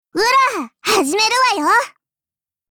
碧蓝航线:塔什干(μ兵装)语音
Cv-70105_battlewarcry.mp3